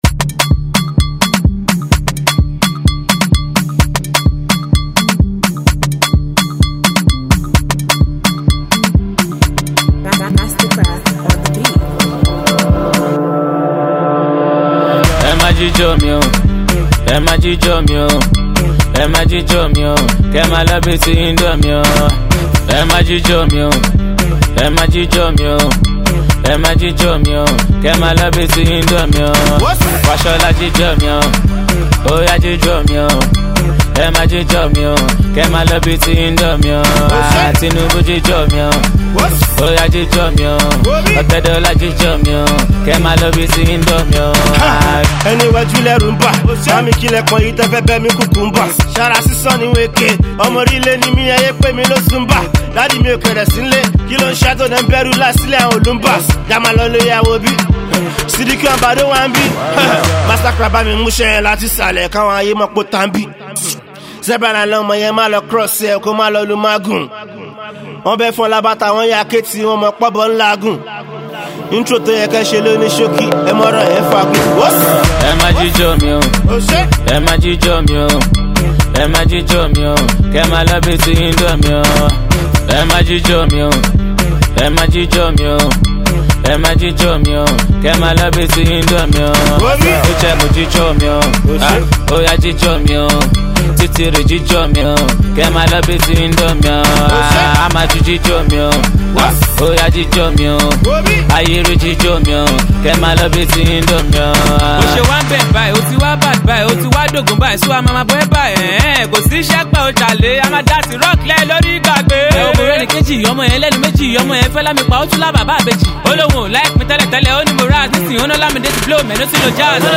indigenous Hip-Hop, Indigenous Pop